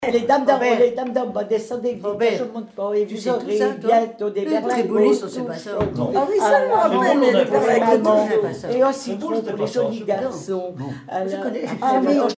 chants brefs - cris de rue
Enquête Douarnenez en chansons
Pièce musicale inédite